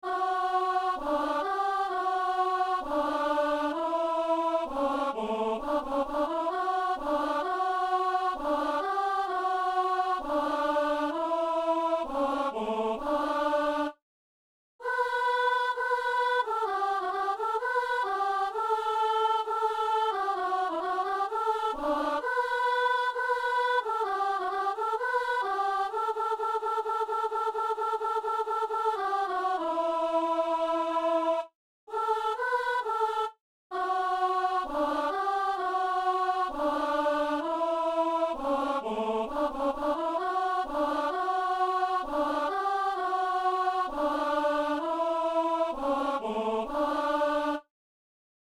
Voix unique